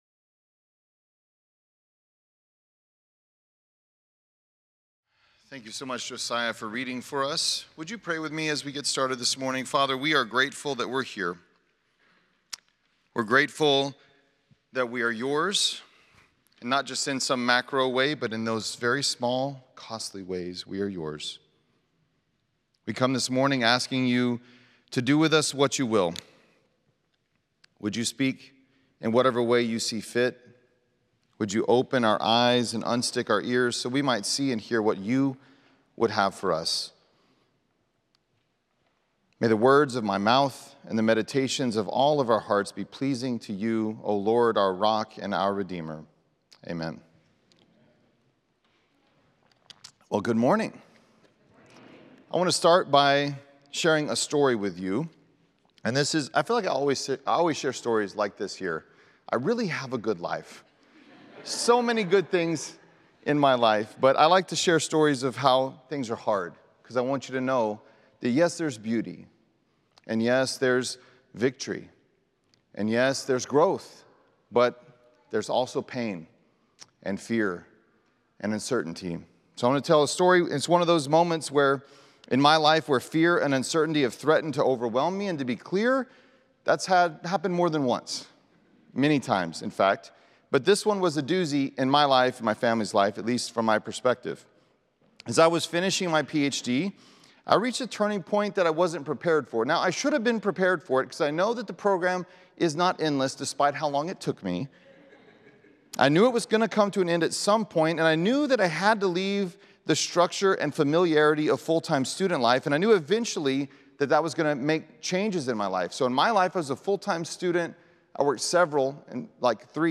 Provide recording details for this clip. Chapel at Estes